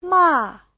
ma4.mp3